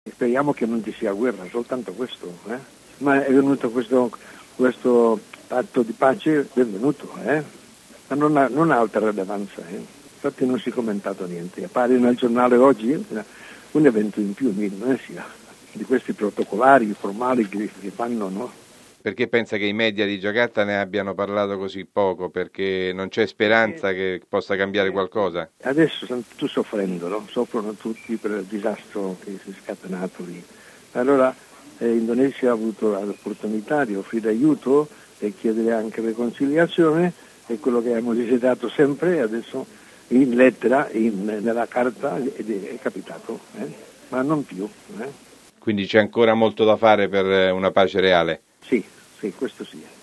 Un altro punto chiave dell'accordo è il rispetto dei diritti umani, oltre ad una limitazione dei movimenti delle truppe dell'esercito indonesiano nel territorio dell'Aceh. Comunque, la strada per la pace è ancora lunga, come testimonia da Giakarta il missionario